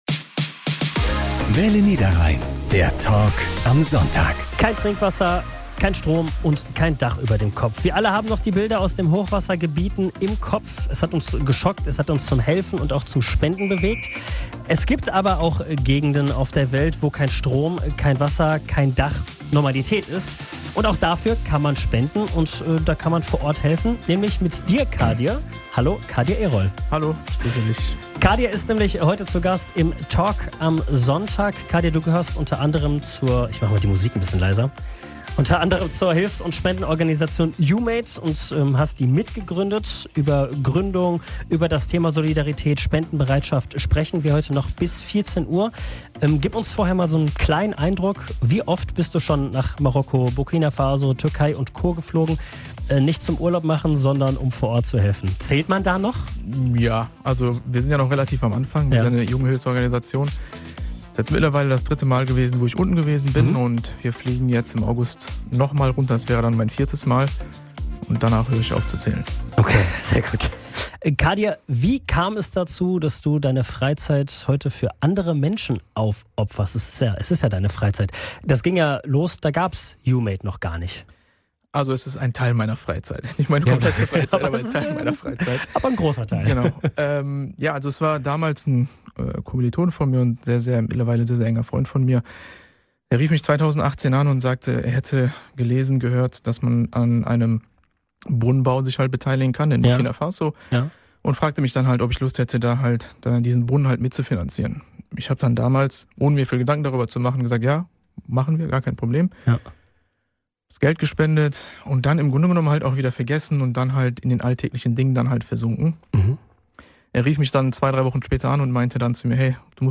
Der Talk am Sonntag